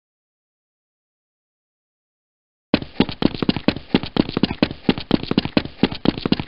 Steps.mp3 Bouton sonore